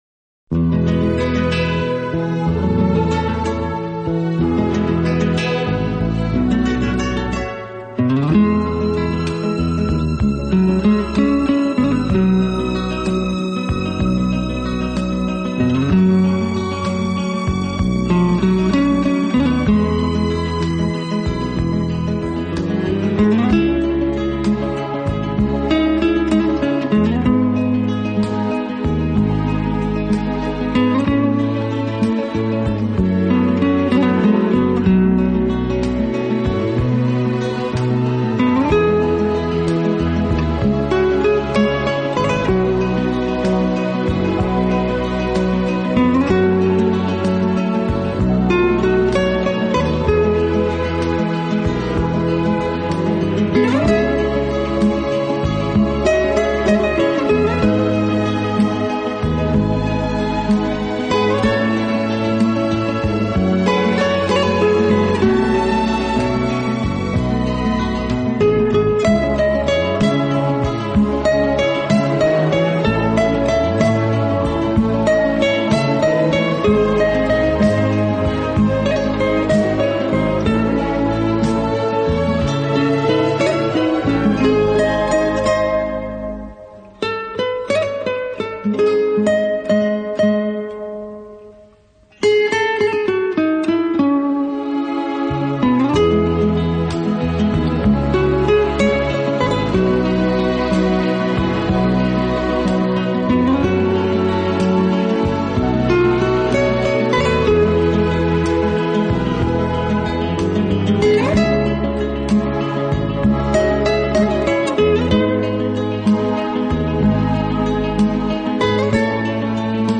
Acoustic（原音）是指原声乐器弹出的自然琴声（原音），制作录音绝对不含味精，乐器历历
心神的旋律。
六根弦拨出的音符如精灵般跳跃，精选18首古典吉它曲。